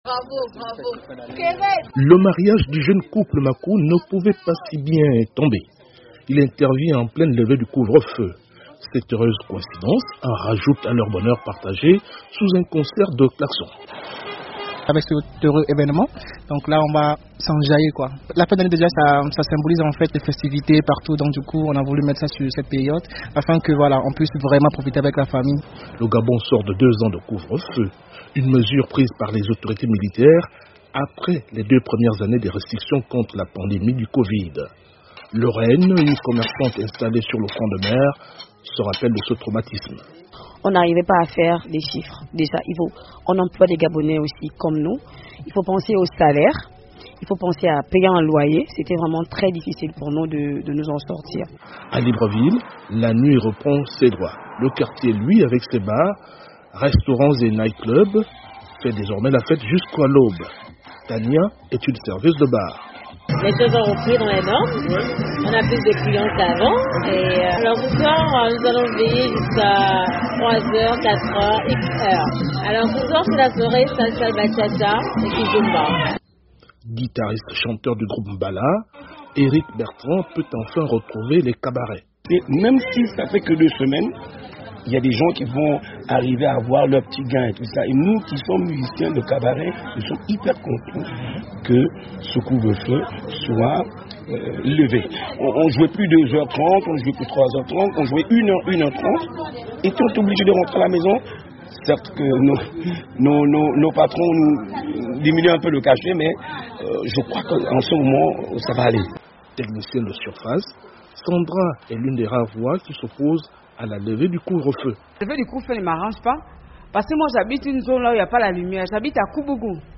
Le gouvernement de transition a décidé de lever le couvre-feu jusqu’à nouvel ordre. À Libreville, la vie reprend des couleurs à l’approche des fêtes de fin d’année. Le reportage